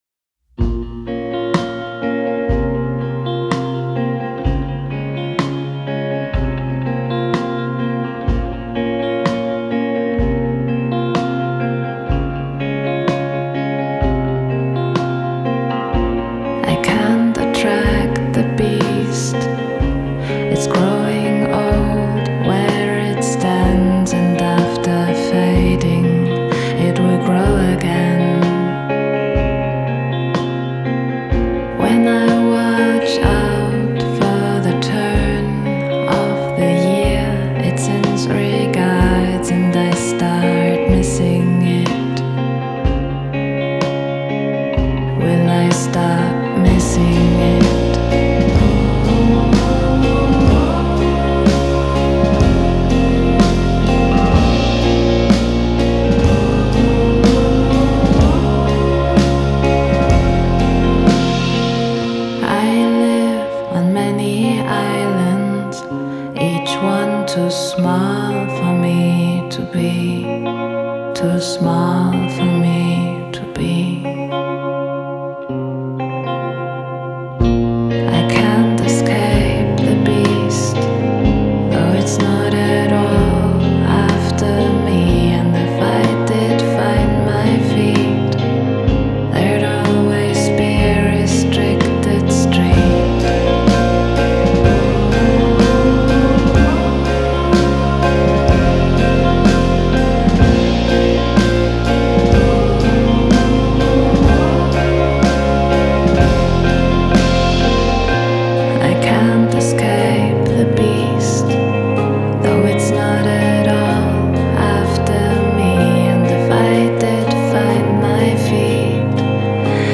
Genre: Dream Pop, Indie Folk, Singer-Songwriter